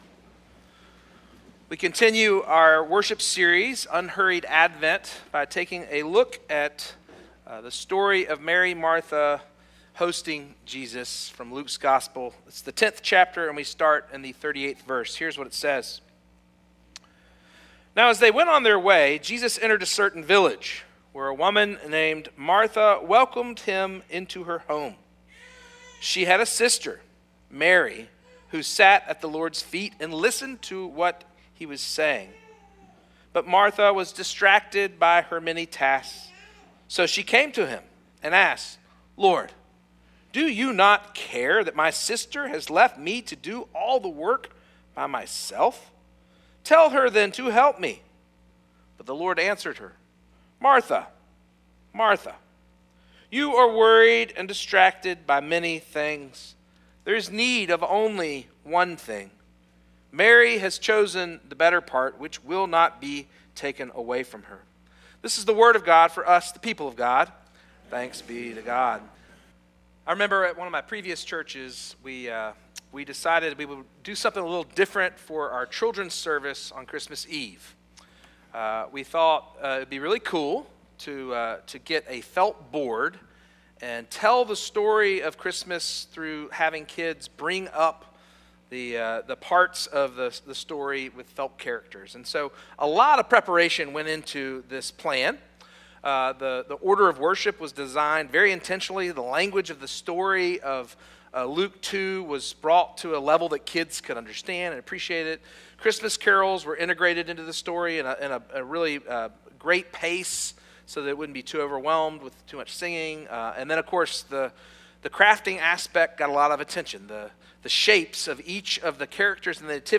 “Unhurried Advent” Sermon Series